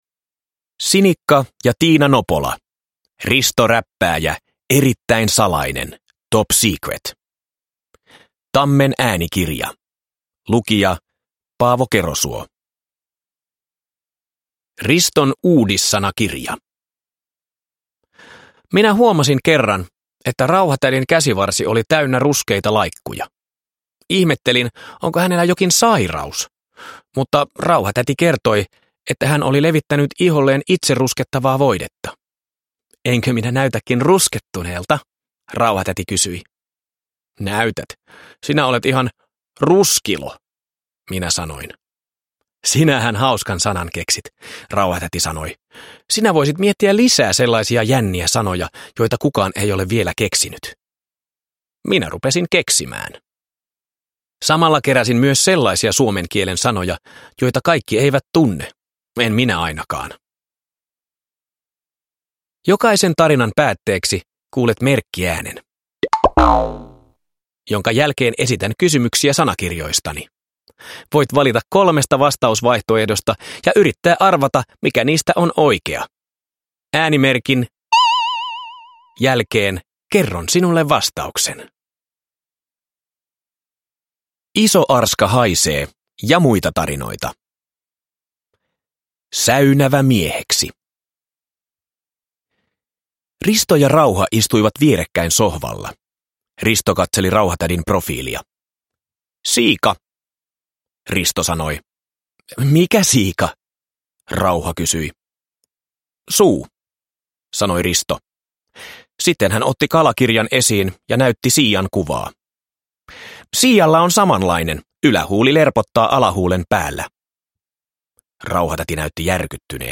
Risto Räppääjä. Erittäin salainen - Top Secret – Ljudbok